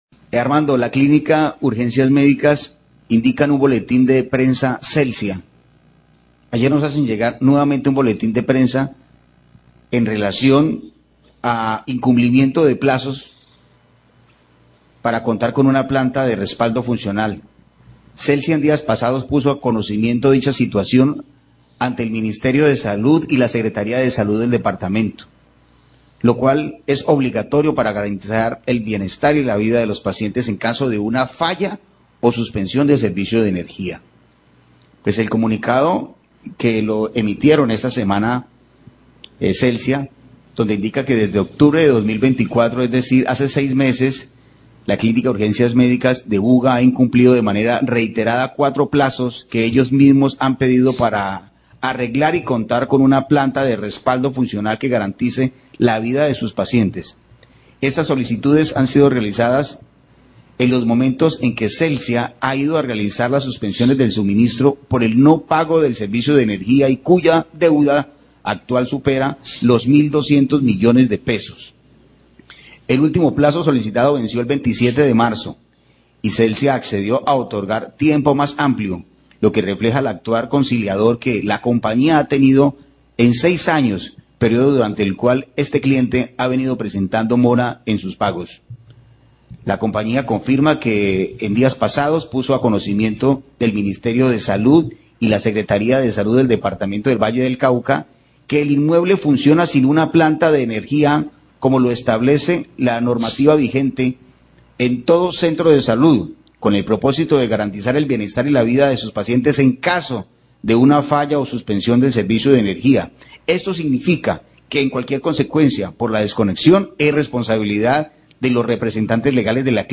Periodistas leen comunicado de prensa de Celsia donde informa que debió proceder a la suspensión del servicio de energía a la Clínica Urgencias Médicas luego que la institución médica no ha pagado una deuda de 6 años por el consumo de energía.